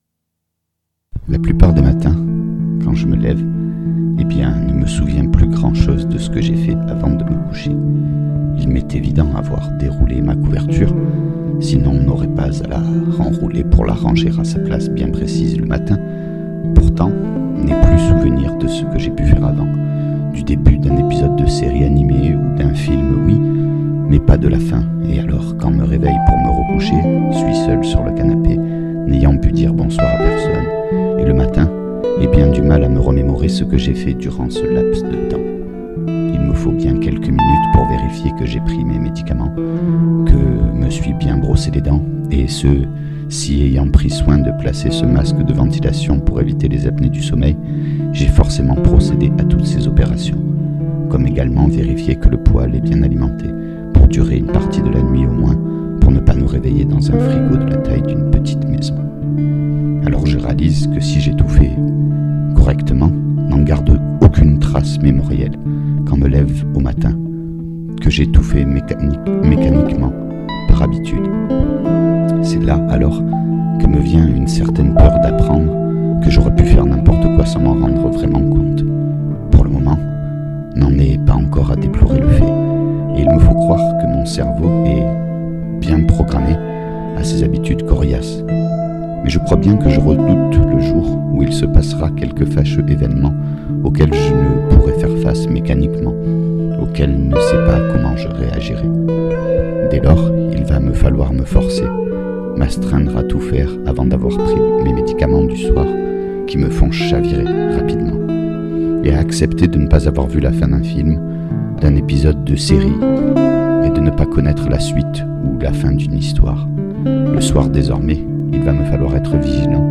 Le principe est tout simple : Un texte, comme une chronique, rédigé(e) avec un stylo sur un cahier (parce que c’est comme ça que j’écris), lu(e) devant un micro et accompagné(e) d’une improvisation à la guitare.
De plus je ne dispose pas d’un excellent matériel (un vieil ordi, une carte son externe M-Audio, un micro chant et une guitare) et je pense pouvoir améliorer la qualité du son, avec le temps et un peu d’investissement, mais prenez plutôt cet exercice comme une expérimentation de ma part, vous serez moins déçu(e)s. A noter : Les dates correspondent aux phases d’écriture, pas à l’enregistrement de la musique, mise en boîte quand il m’en vient la motivation.